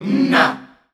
Index of /90_sSampleCDs/Voices_Of_Africa/ShortChantsShots&FX